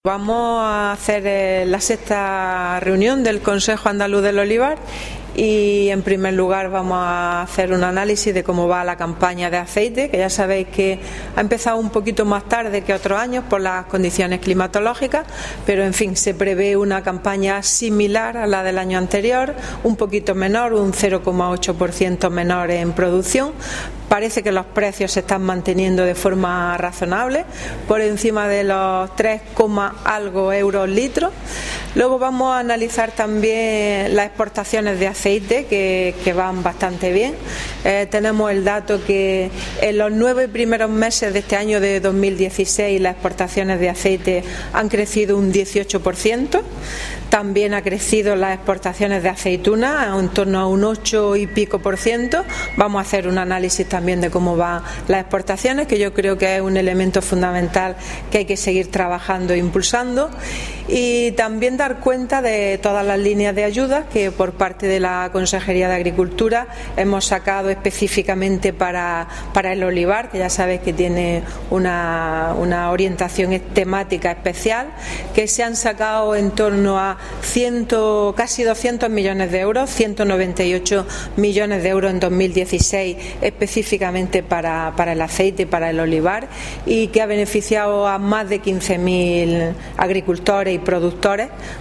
Declaraciones de Carmen Ortiz sobre el Consejo Andaluz delOlivar